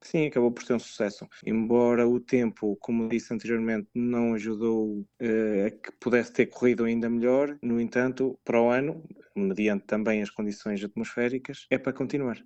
Uma das novidades deste ano foi a prova desportiva com tratores, que envolveu oito agricultores e que, segundo o autarca, passará a integrar o programa das próximas edições: